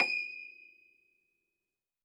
53r-pno23-D5.aif